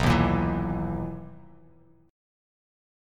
BM11 Chord
Listen to BM11 strummed